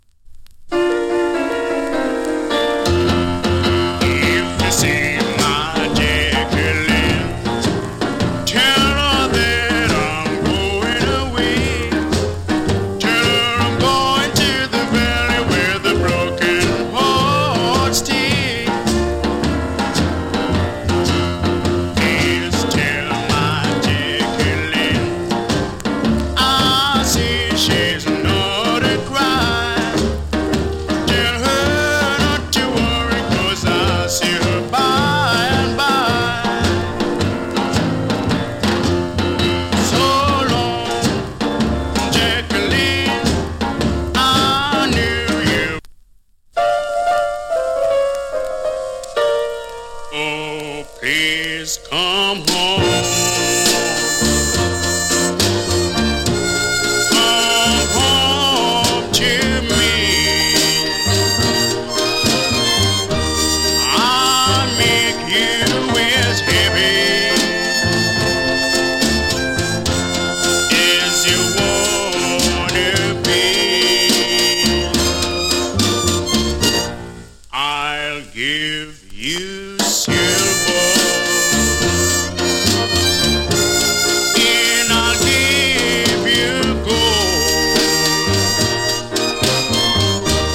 61年 FINE VOCAL 初期モノ !